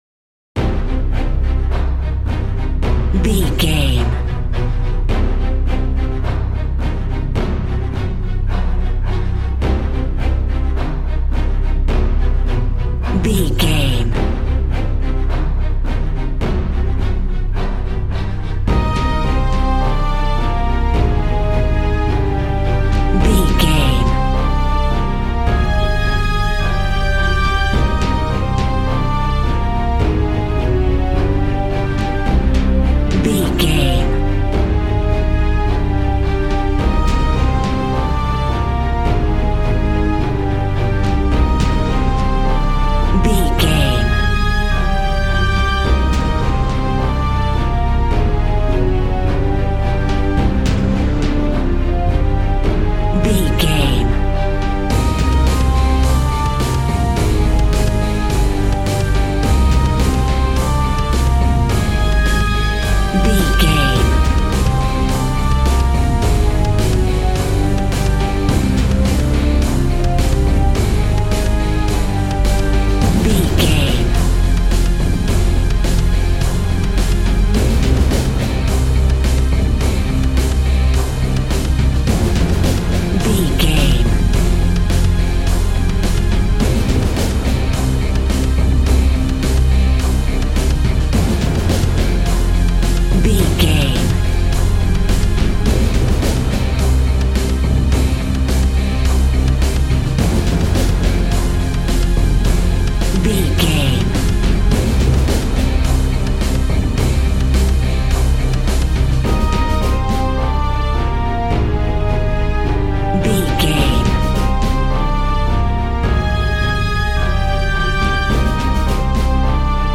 Epic / Action
Fast paced
In-crescendo
Uplifting
Ionian/Major
strings
brass
percussion
synthesiser